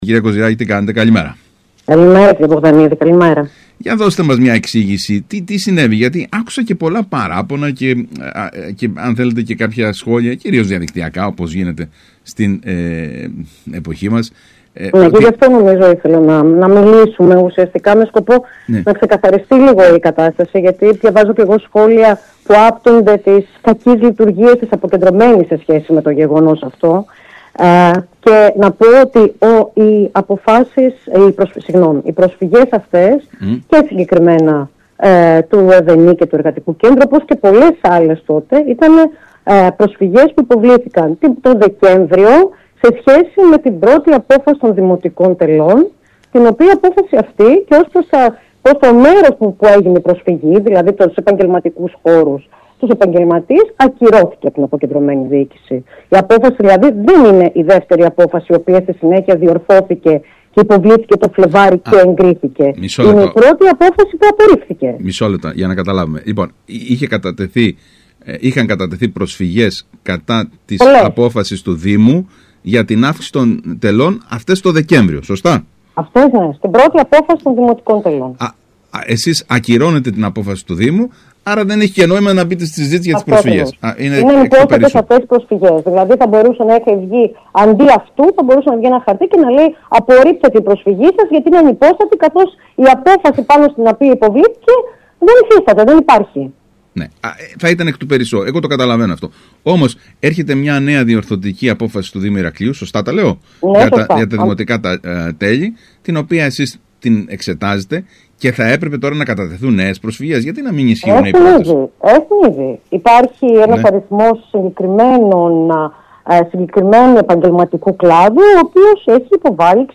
Η Γραμματέας της Αποκεντρωμένης Διοίκησης Κρήτης Μαρία Κοζυράκη μίλησε στον ΣΚΑΙ Κρήτης για το πολυσυζητημένο θέμα των δημοτικών τελών.
Ακούστε εδώ όσα είπε η Μαρία Κοζυράκη στον ΣΚΑΙ Κρήτης: